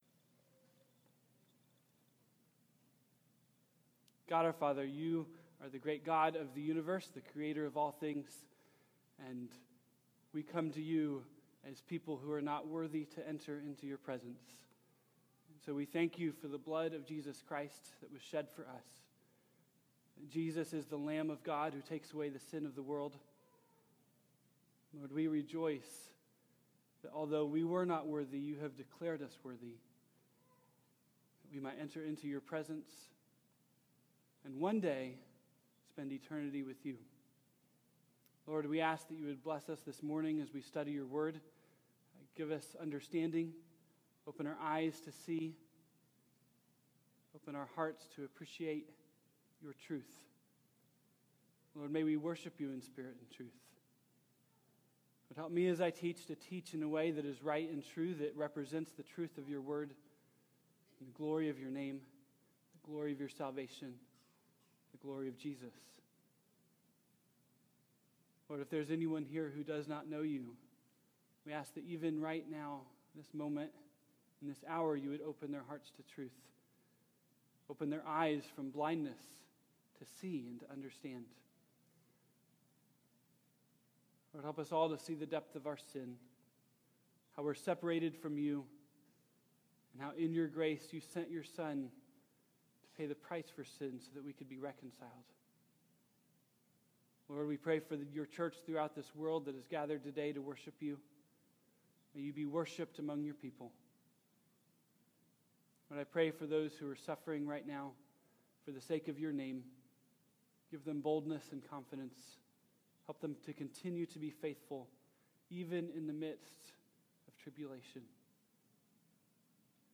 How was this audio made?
2016 ( Sunday AM ) Bible Text